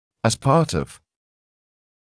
[æz pɑːt ɔv][эз паːт ов]как часть (чег-то)